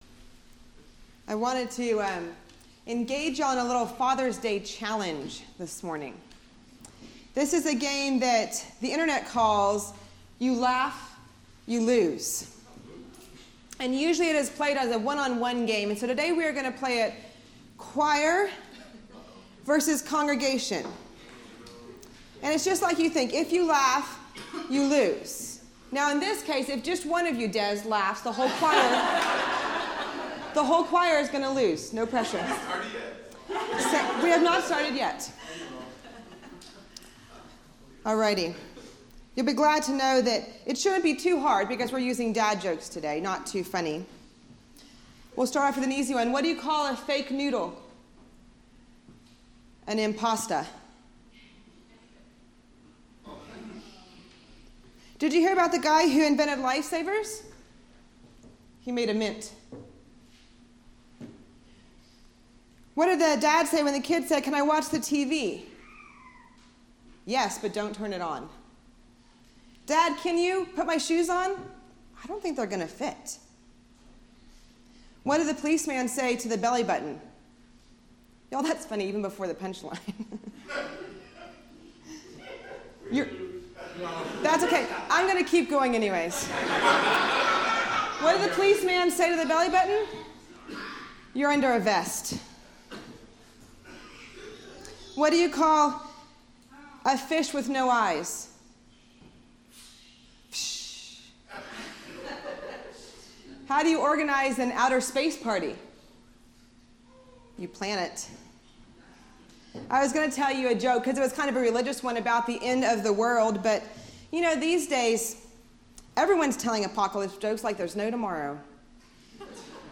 “David and Jonathan” June 17, 2018 – First Presbyterian Church